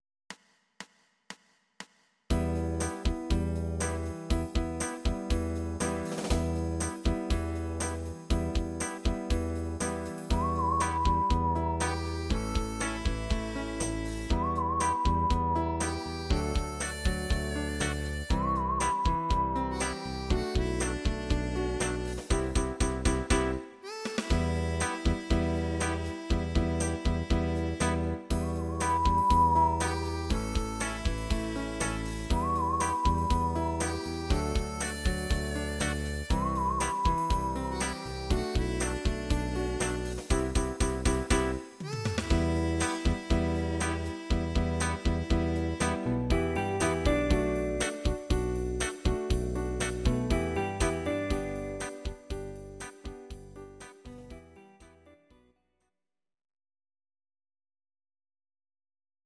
Audio Recordings based on Midi-files
Oldies, Musical/Film/TV, Instrumental, 1960s